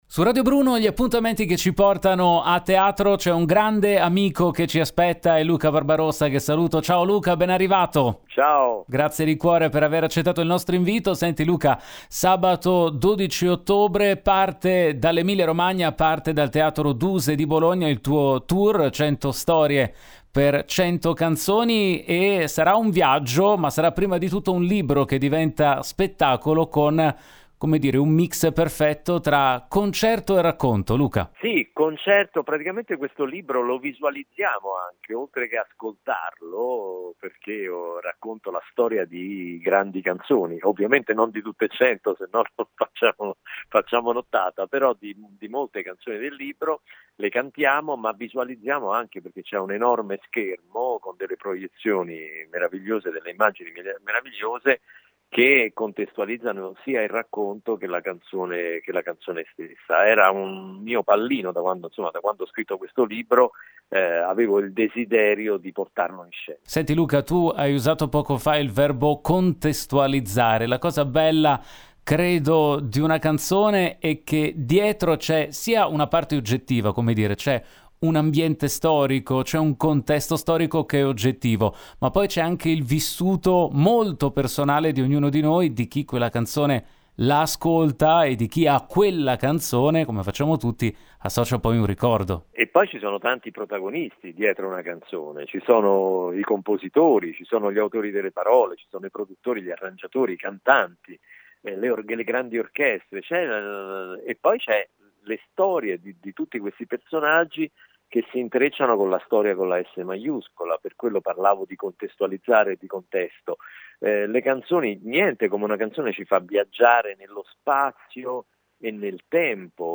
Home Magazine Interviste Luca Barbarossa presenta il tour “Cento storie per cento canzoni”
Le parole del cantautore